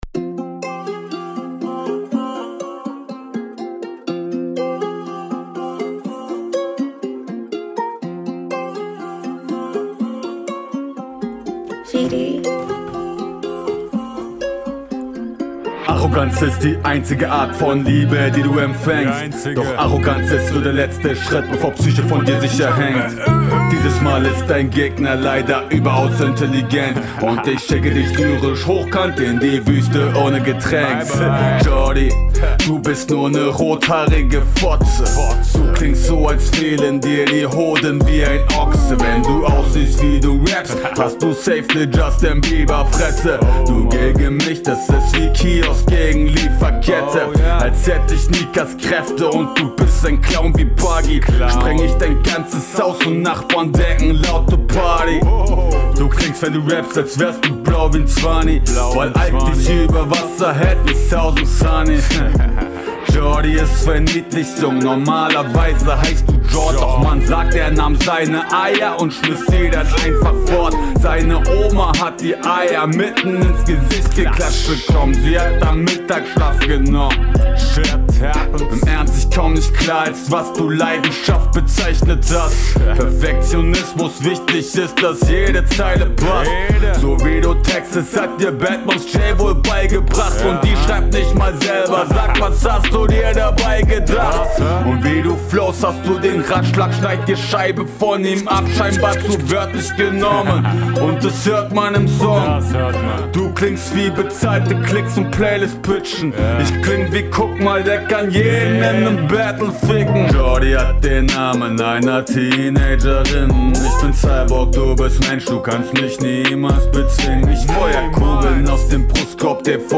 Ok geflowt auf dem langsamen Beat, wobei ich mir doch mehr Variationen wie in der …